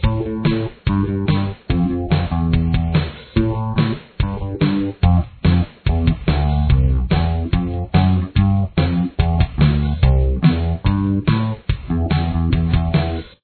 Tempo: 143 beats per minute
Key Signature: A minor
Bass Guitar